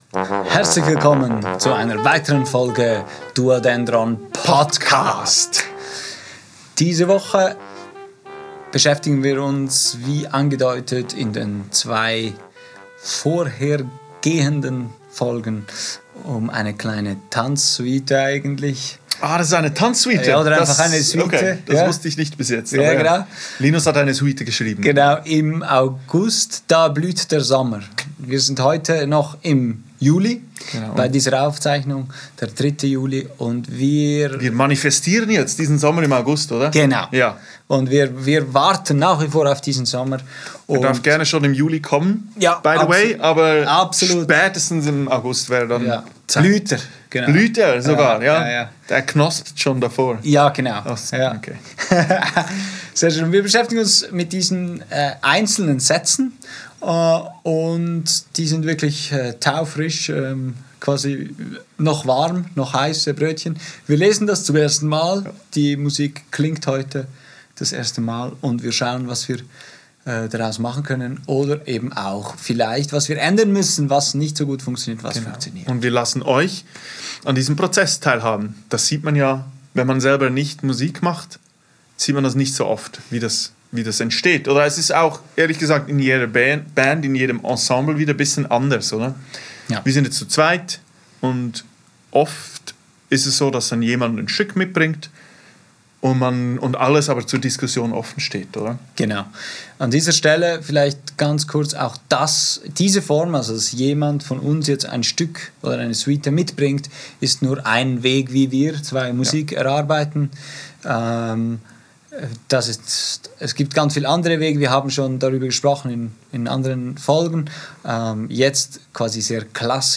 Ihr dürft uns beim Erarbeiten dieses Stückes begleiten und einen intimen Blick hinter die Kulissen werfen.